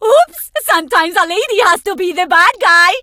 diva_kill_vo_01.ogg